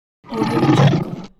Roar Of Lion Téléchargement d'Effet Sonore
Roar Of Lion Bouton sonore